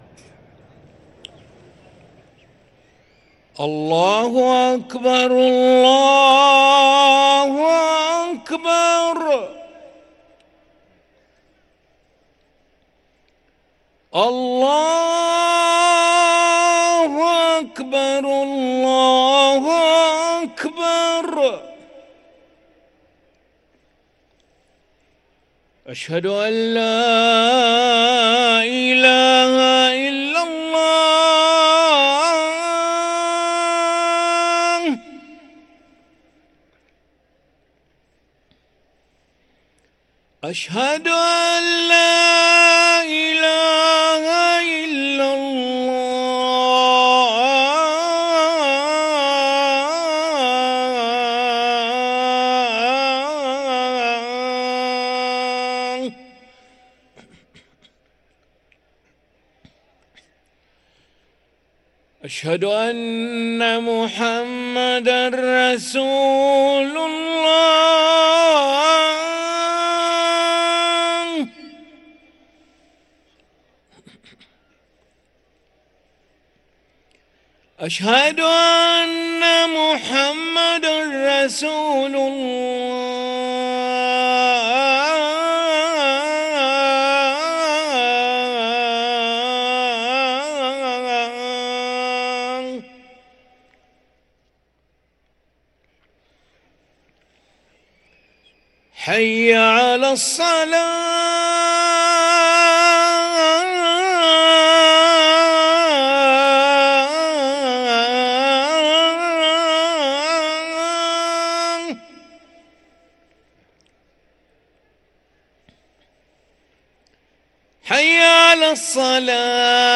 أذان العشاء للمؤذن علي ملا الأحد 3 شوال 1444هـ > ١٤٤٤ 🕋 > ركن الأذان 🕋 > المزيد - تلاوات الحرمين